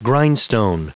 Prononciation du mot grindstone en anglais (fichier audio)
Prononciation du mot : grindstone